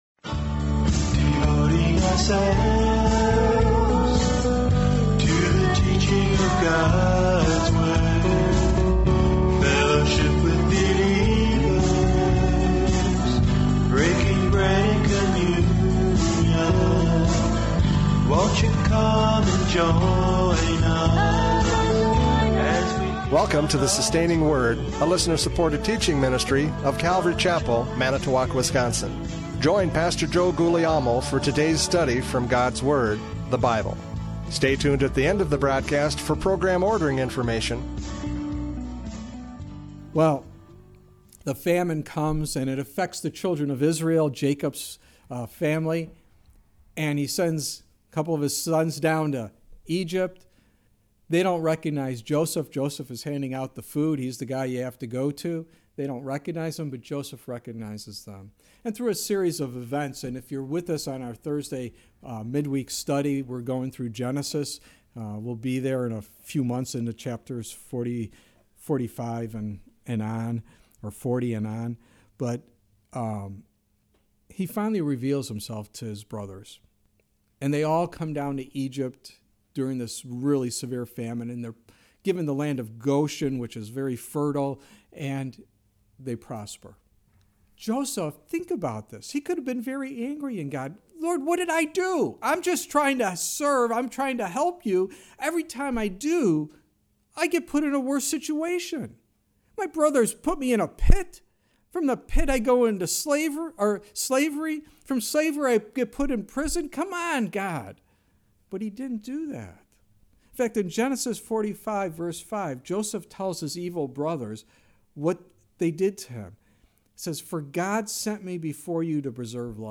John 12:23-43 Service Type: Radio Programs « John 12:23-43 The Messiah Teaches!